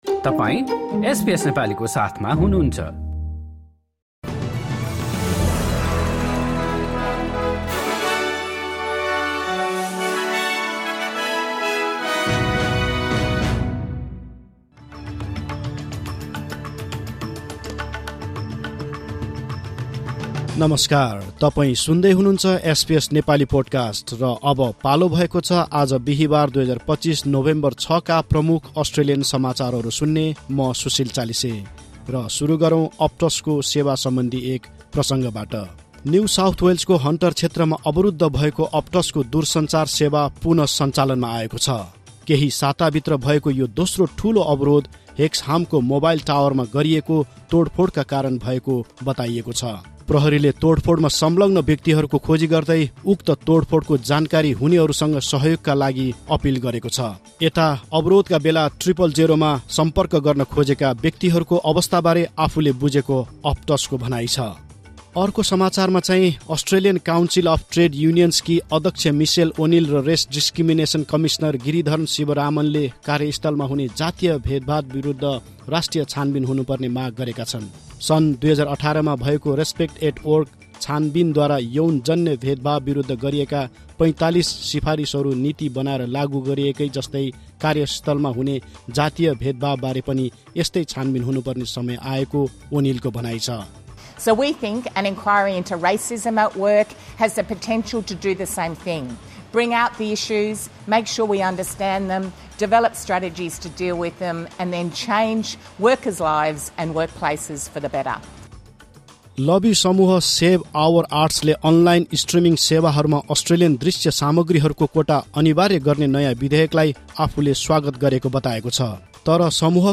SBS Nepali Australian News Headlines: Thursday, 6 November 2025